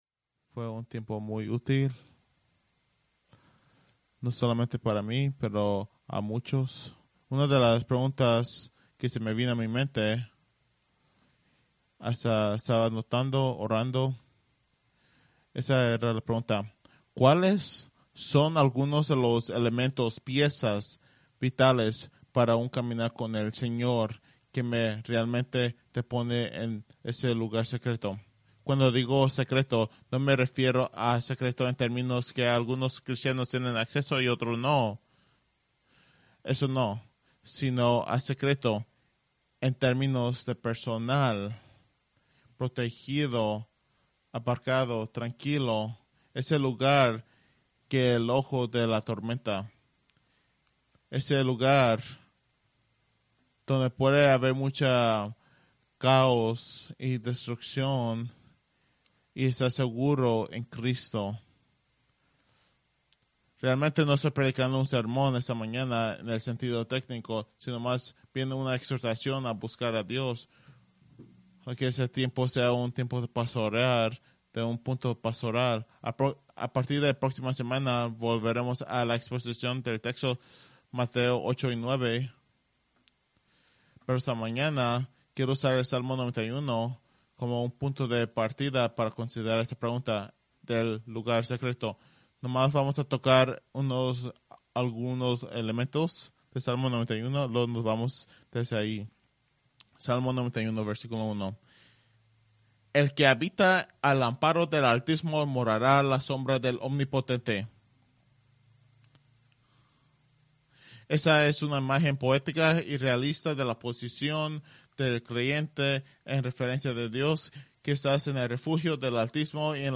Preached August 25, 2024 from Salmo 91